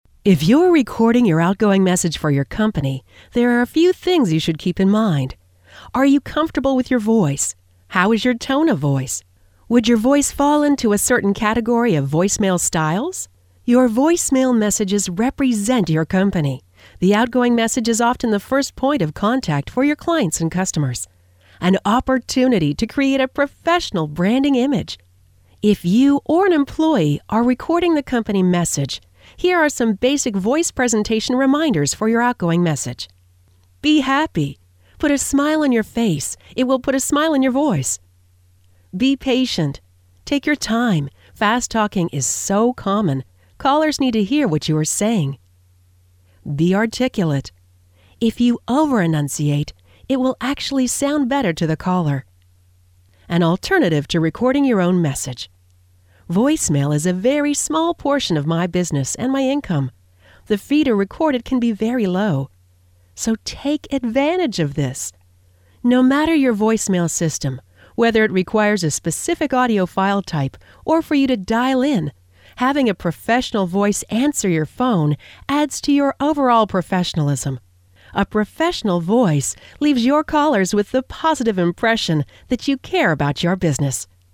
CLICK TO HEAR AUDIO VERSION OF BLOG>>